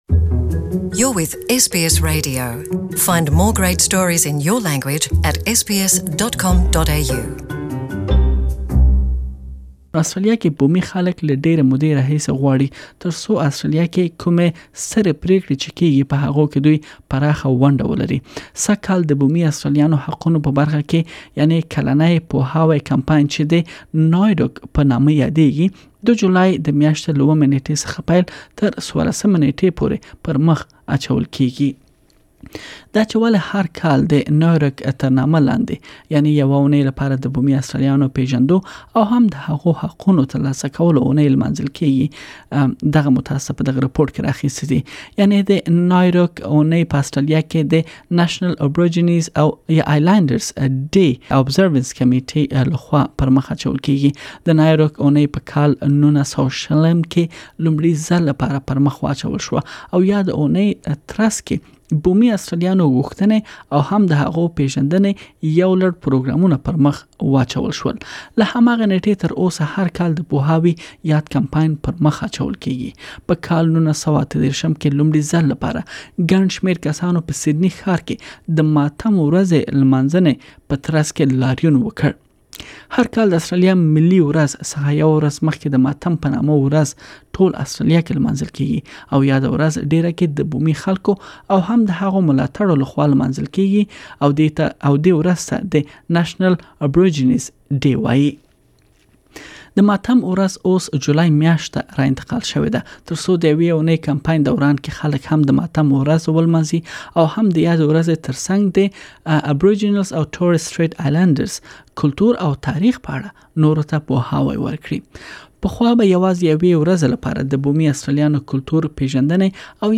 Please listen to the full report in Pashto.